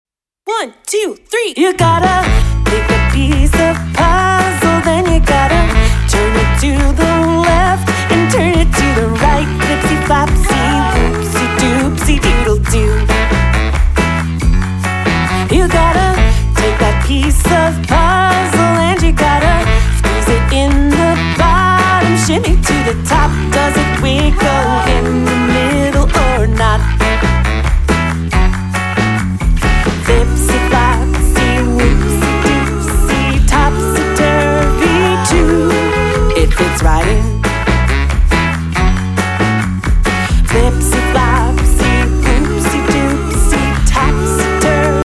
bass line
some fun harmonies, a little baritone sax
Demo MP3